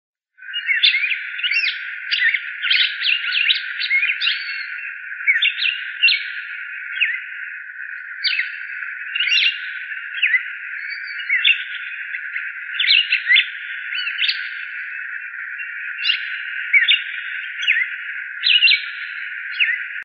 Cardenal Común (Paroaria coronata)
Nombre en inglés: Red-crested Cardinal
Fase de la vida: Adulto
Localidad o área protegida: Reserva Ecológica Costanera Sur (RECS)
Condición: Silvestre
Certeza: Vocalización Grabada